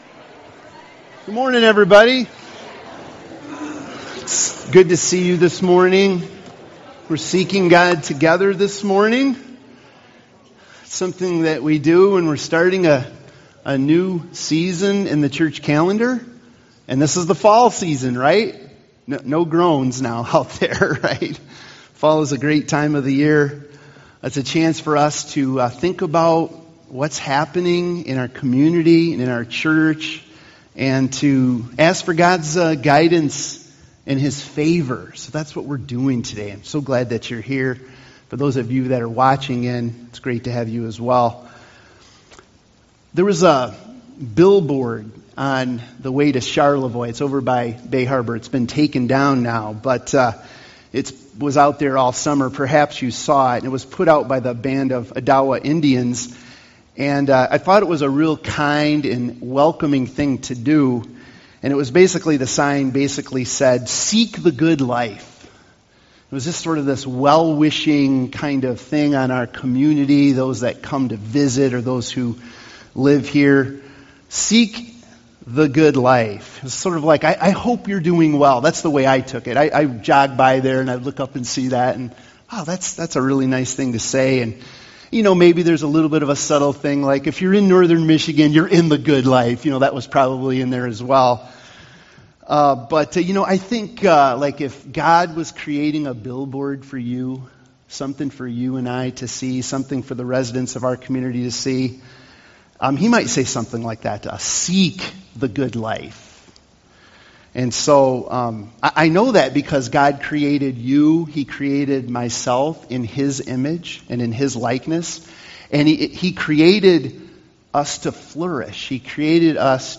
Seeking The Lord Together Service Type: Sunday Morning « Conversations